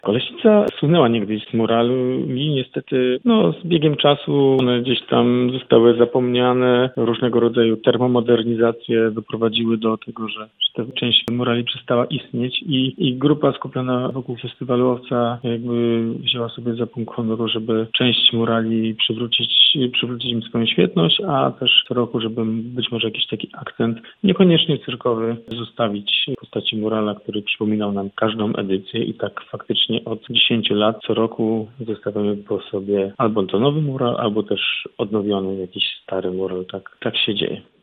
Na naszej antenie zdradził, co wyjątkowego przygotowano w programie tegorocznej edycji festiwalu z racji jubileuszu.